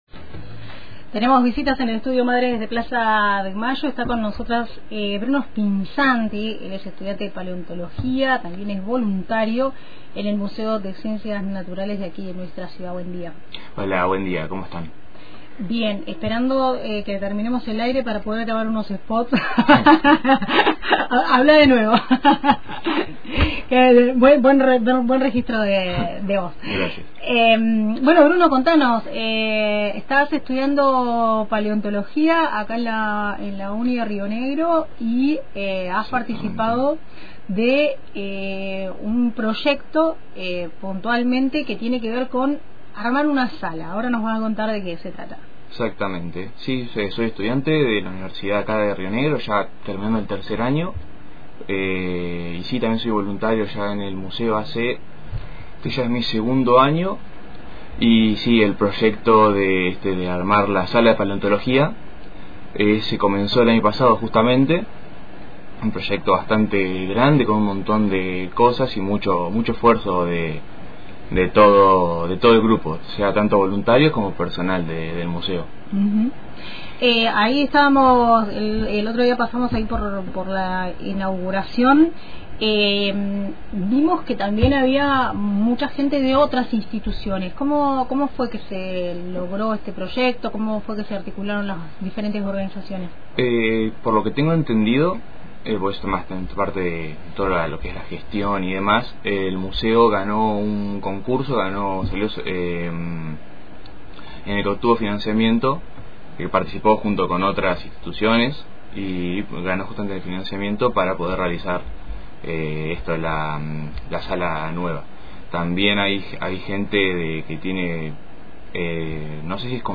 Además, en la entrevista repasó el recorrido de la sala: cómo se decidió la organización por formaciones geológicas, el criterio de selección de especies y el vínculo entre la paleontología local y la identidad regional.